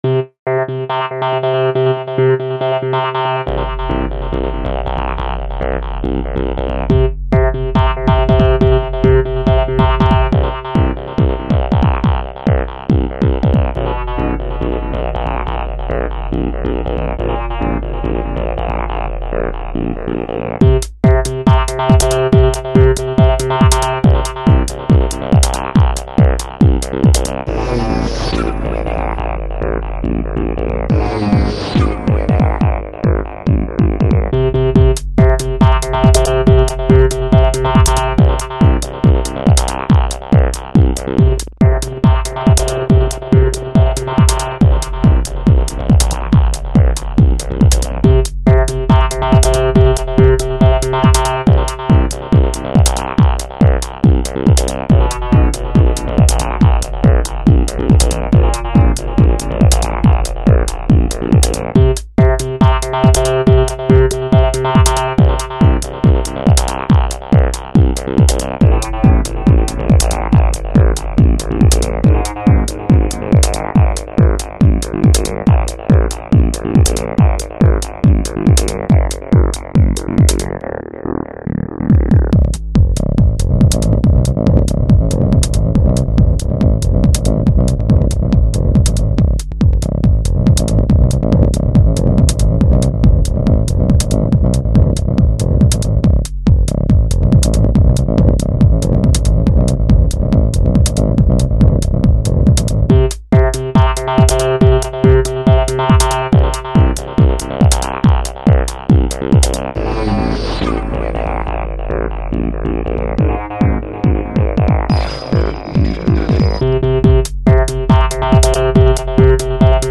mono-synth-mix